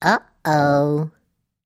На этой странице собраны разнообразные звуки гномов: от добродушного смеха до зловещего бормотания.